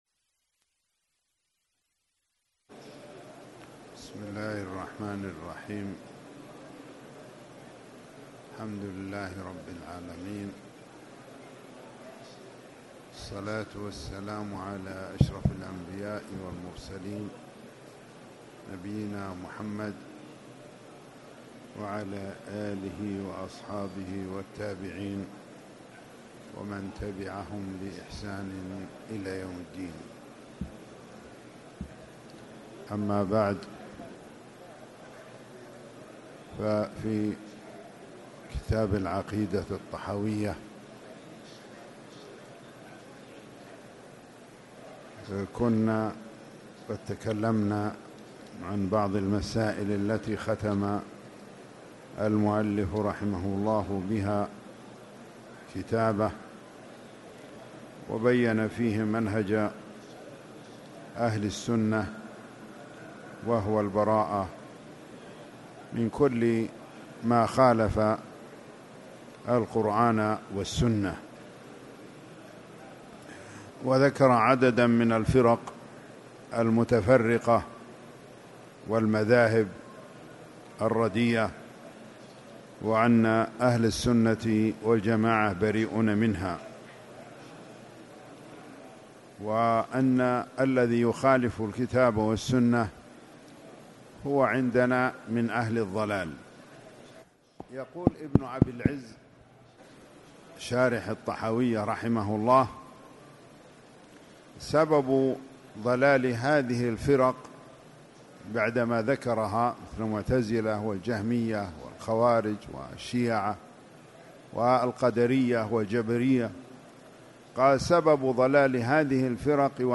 تاريخ النشر ١٠ رمضان ١٤٣٨ هـ المكان: المسجد الحرام الشيخ